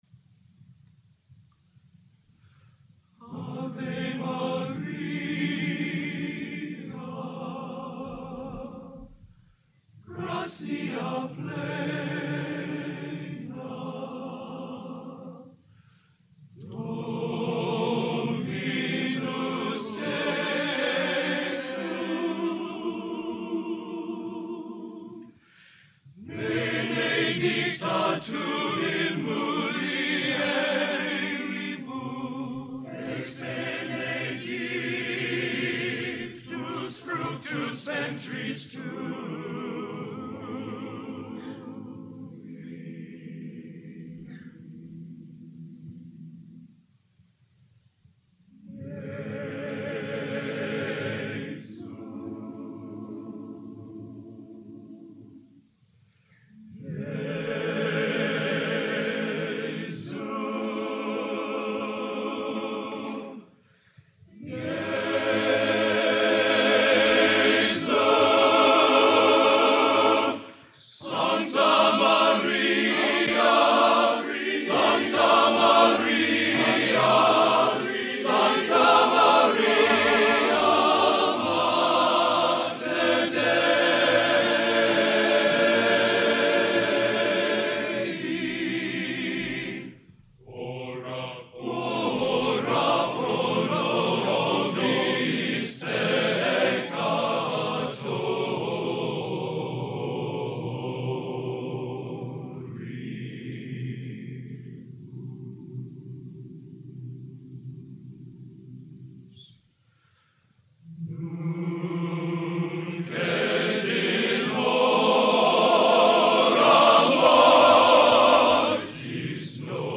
Genre: A Cappella Classical Sacred | Type: End of Season